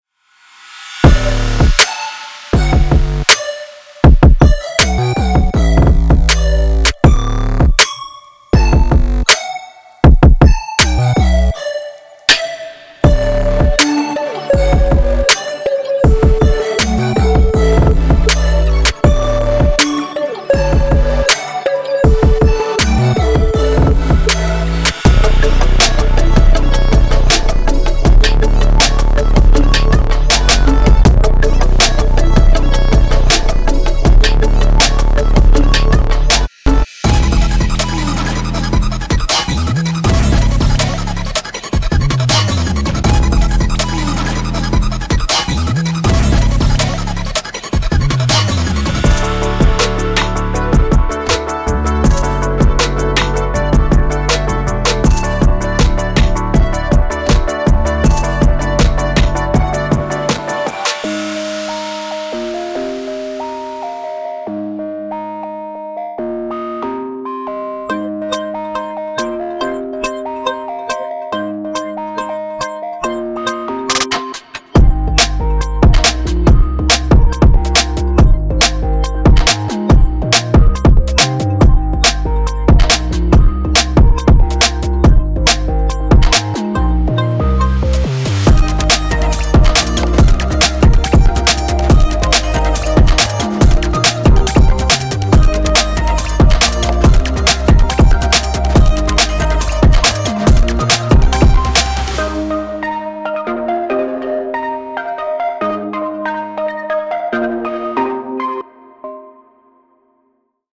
Genre:Future Pop
ポップ、パンク、エレクトロニックのカオスが衝突する、ハイパーキネティックなサウンドコレクションです。
ハイパーで、ラウドで、そしてためらいなく感情的。
デモサウンドはコチラ↓